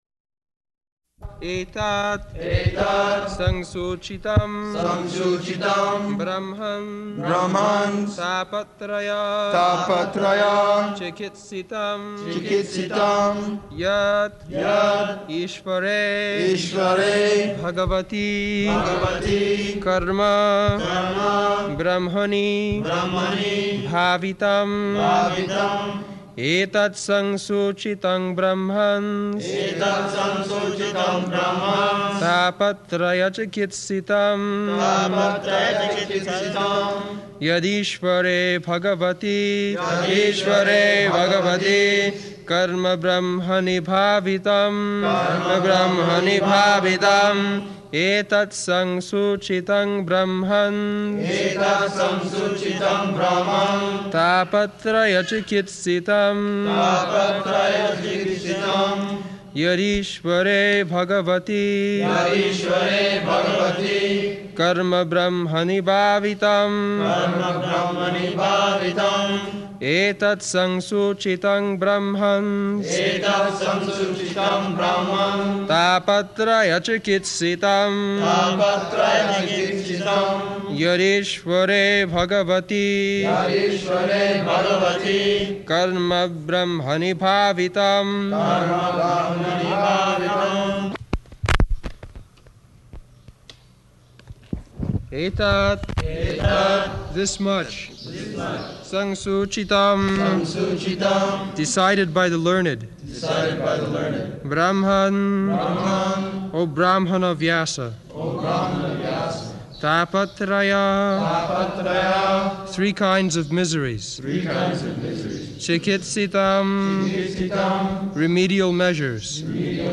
August 13th 1974 Location: Vṛndāvana Audio file
[devotees repeat]